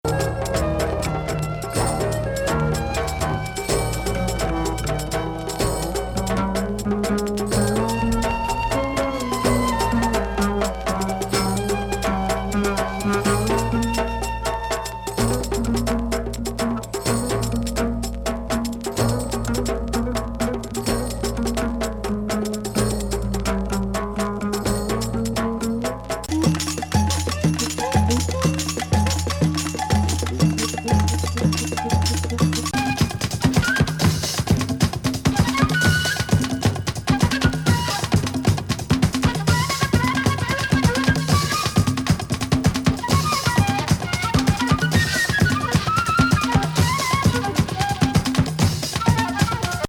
標高高め秘術サイケ・トライバル
サンバ